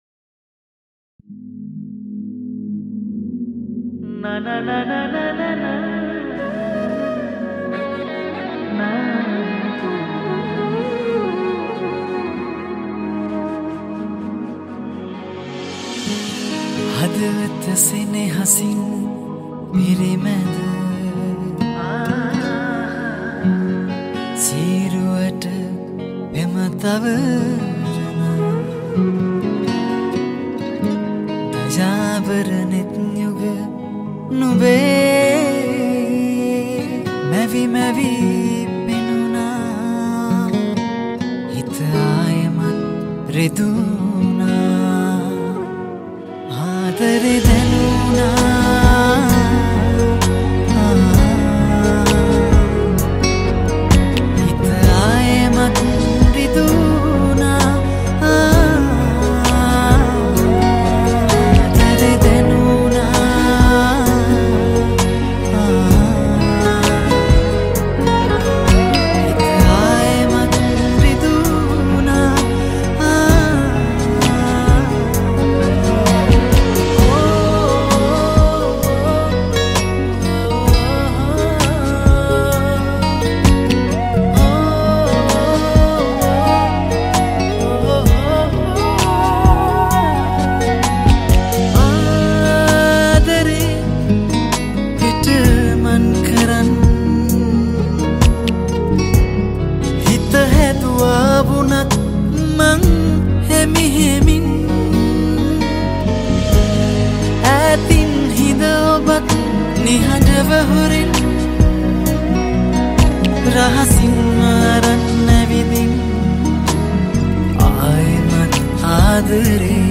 Vocals
Guitars
Flutes